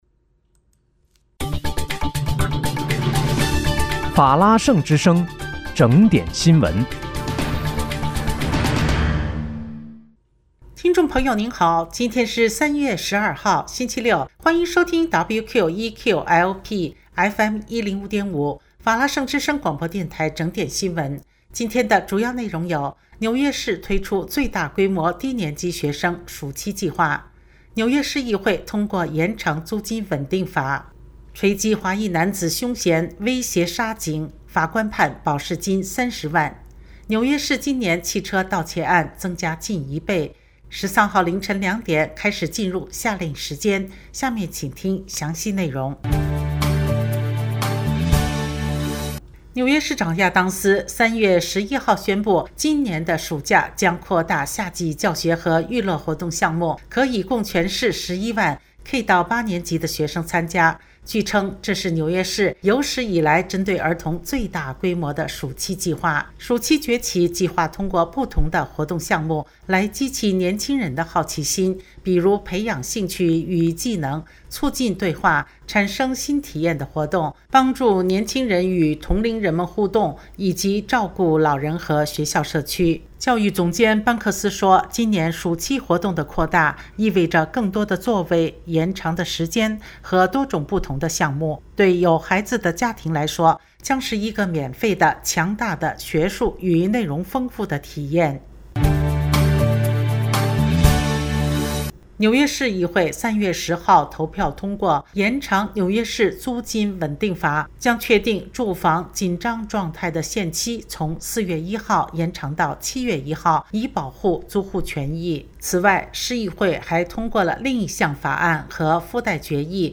3月12日（星期六）纽约整点新闻